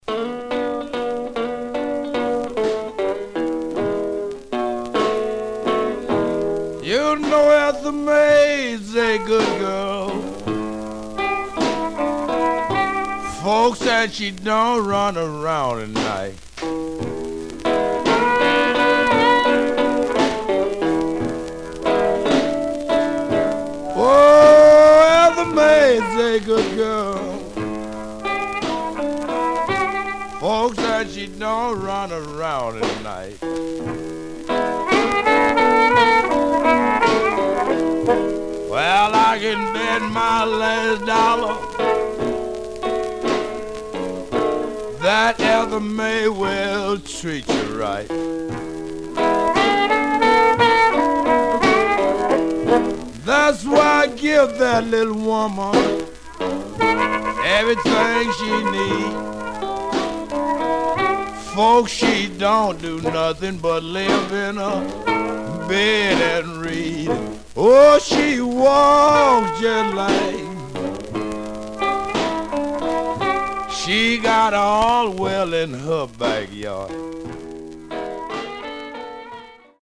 Mono
Rythm and Blues